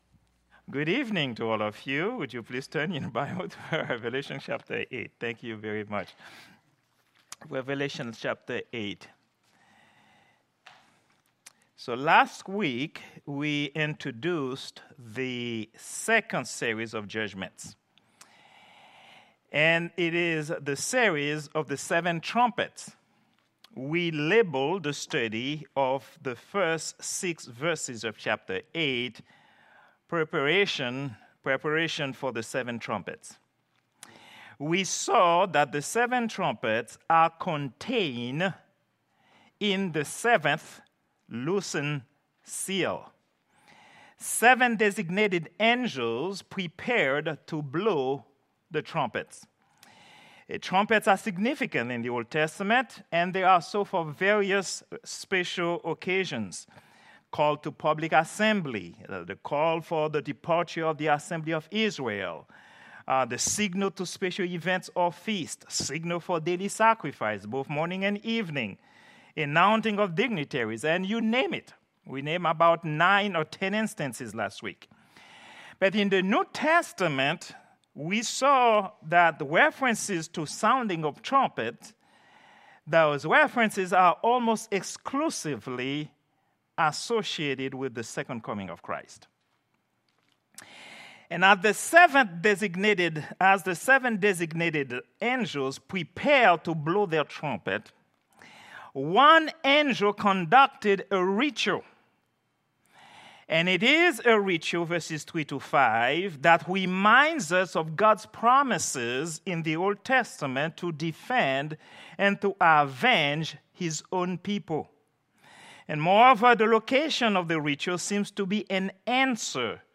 Prayer_Meeting_09_04_2024.mp3